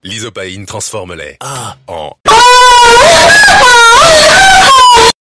lysopaine ahh koula Meme Sound Effect